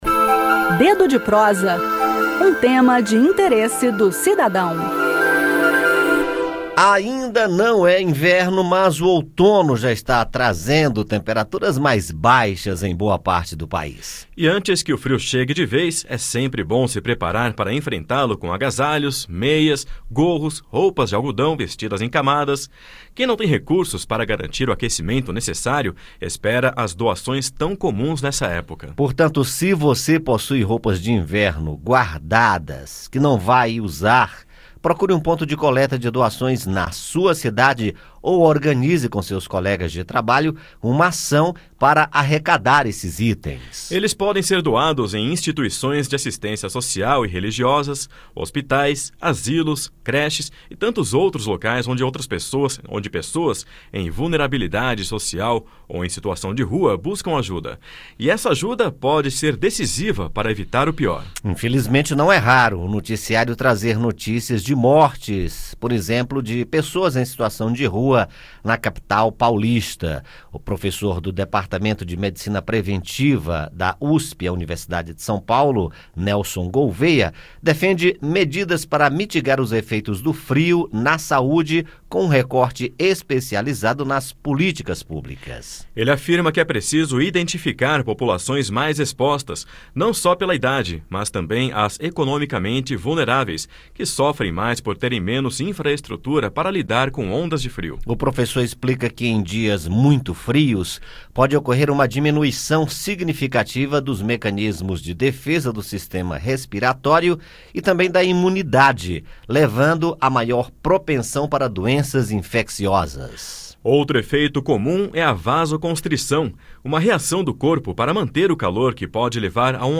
O inverno ainda não chegou, mas o outono já traz temperaturas mais baixas em todo o país. No bate-papo desta terça-feira saiba como ajudar os mais vulneráveis com doações. Procure pontos de coleta de cobertores e roupas de inverno ou faça uma ação social com amigos, familiares ou colegas de trabalho.